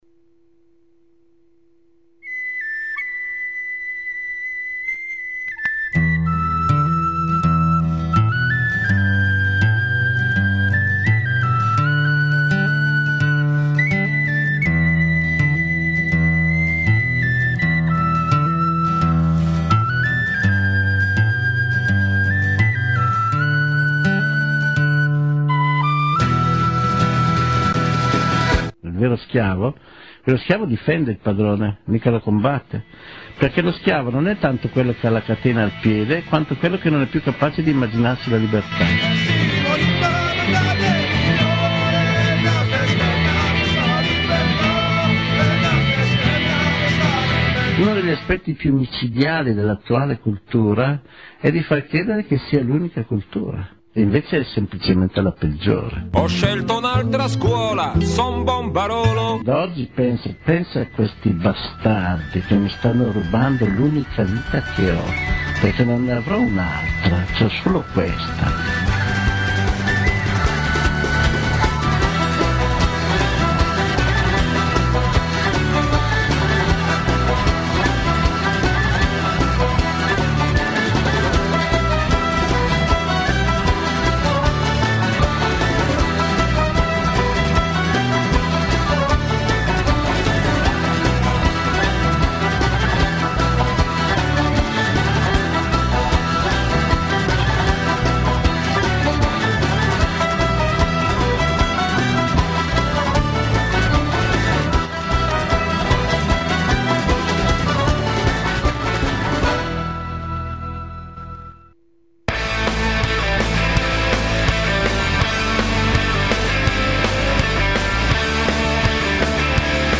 -Storia dell'anarchia in Italia (audio)- (puntata radio da "Il clandestino")